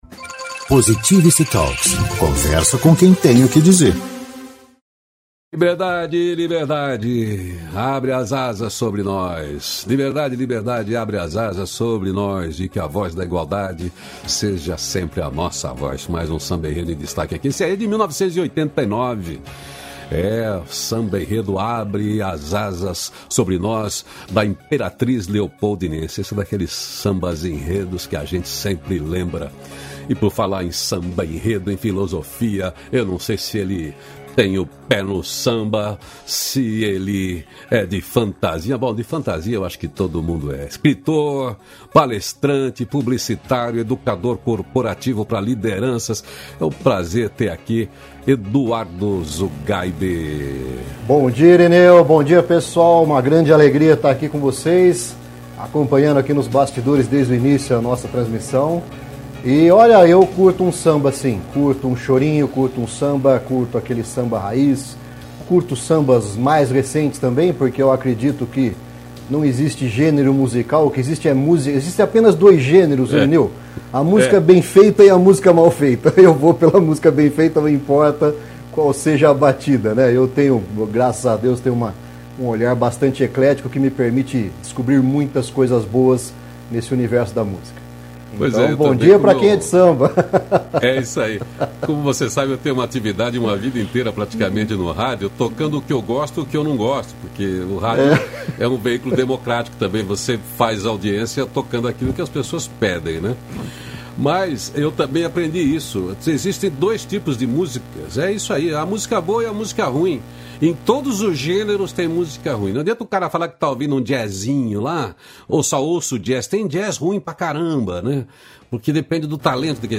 268-feliz-dia-novo-entrevista.mp3